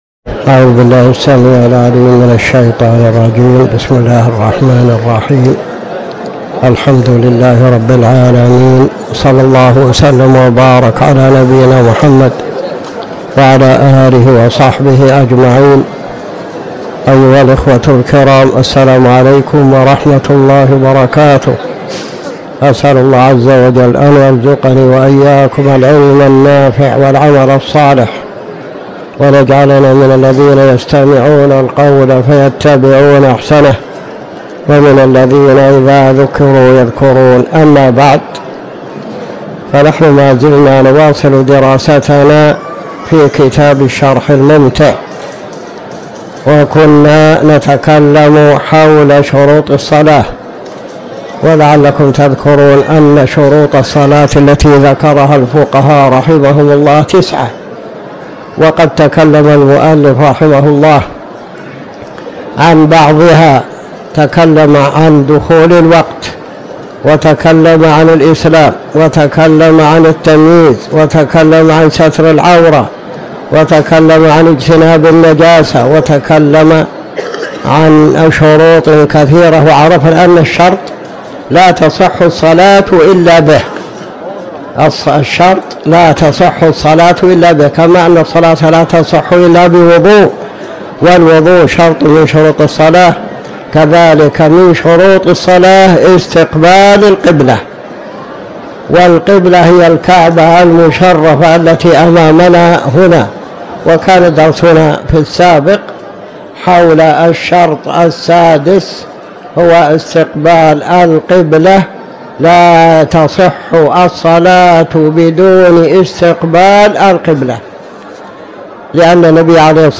تاريخ النشر ٢٠ جمادى الآخرة ١٤٤٠ هـ المكان: المسجد الحرام الشيخ